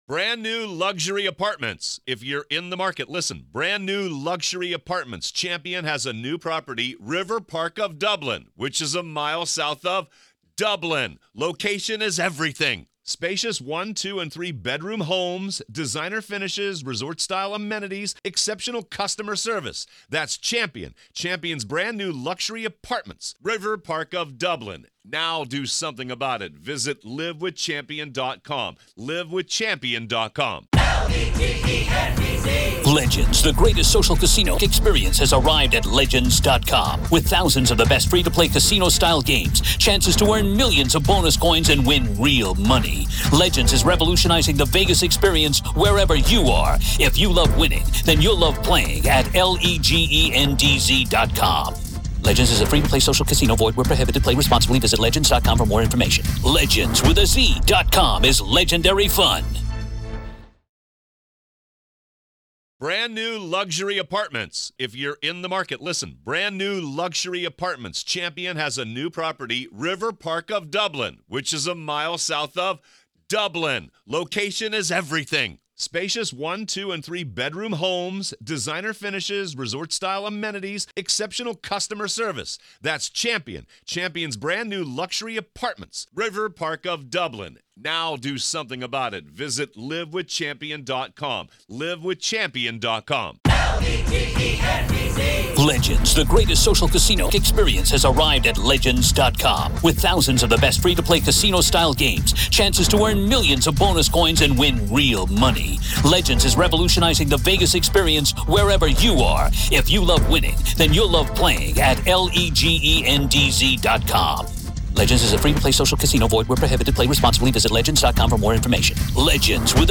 Former felony prosecutor